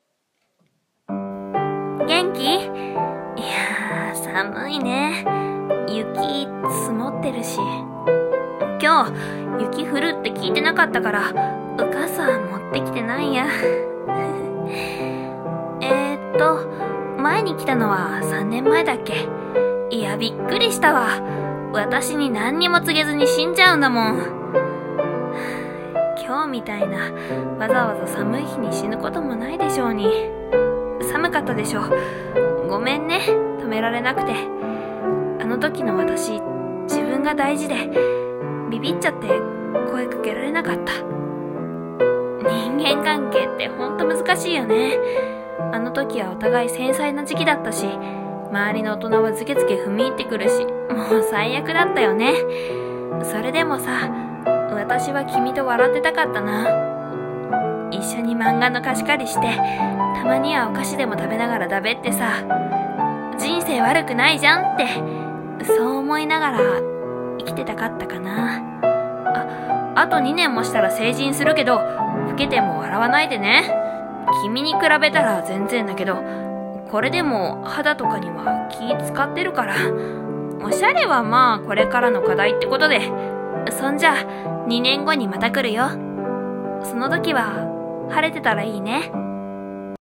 【声劇台本】
女性ver